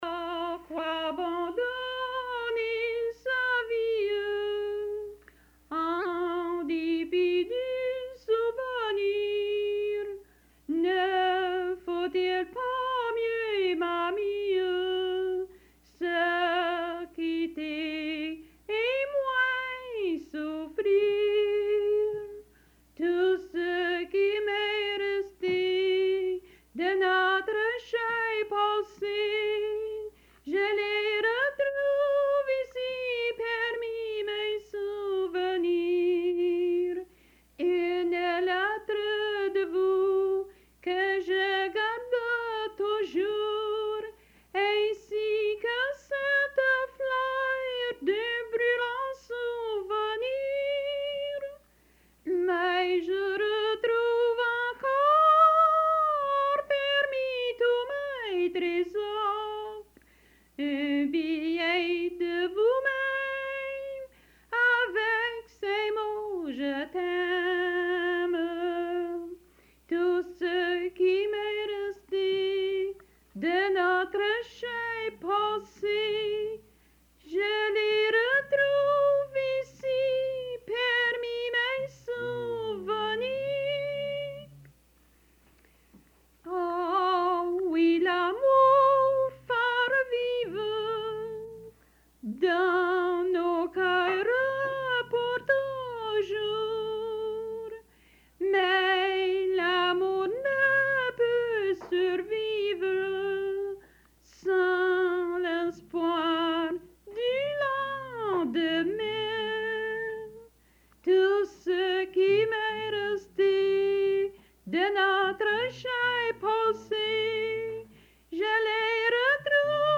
Folk Songs, French--New England
Song